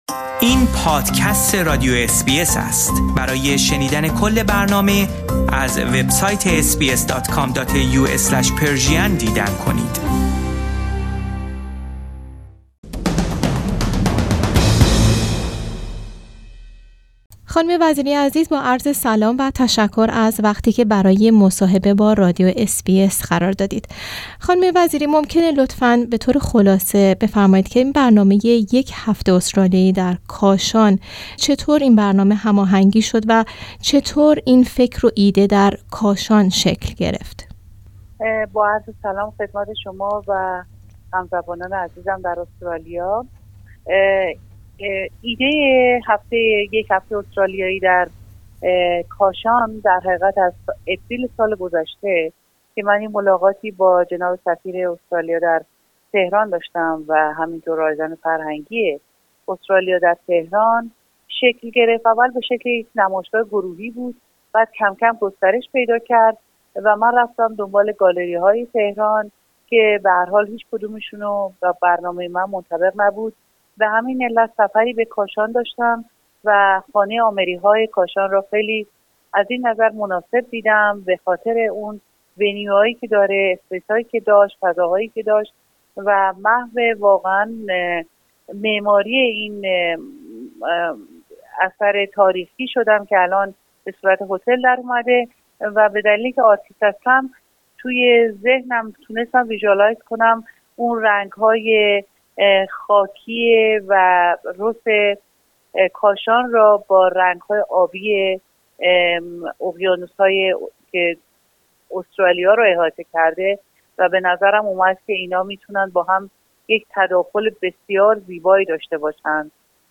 This interview is in Persian.